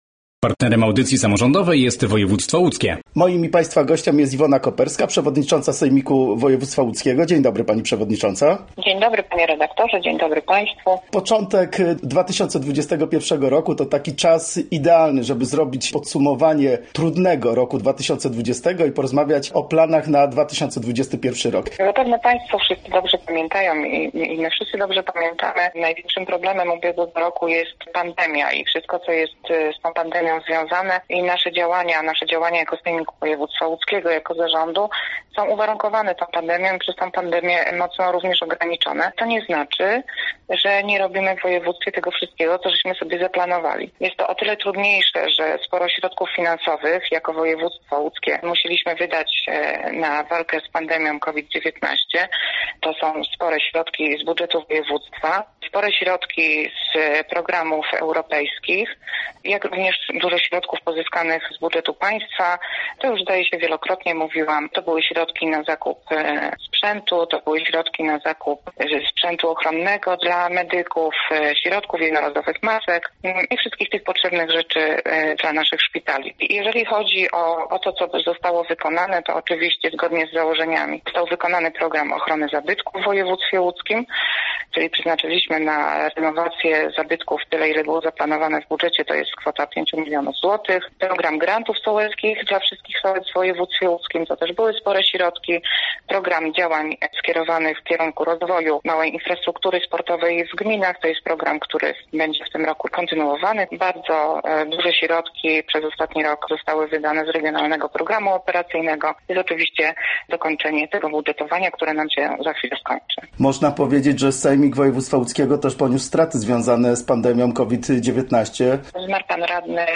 Gościem Radia ZW była Iwona Koperska, przewodnicząca Sejmiku Województwa Łódzkiego